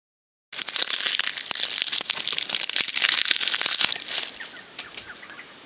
地、水、火、风竞赛 " 火焰噼啪作响(无房)
标签： 噼啪 噼啪作响 余烬 火焰
声道立体声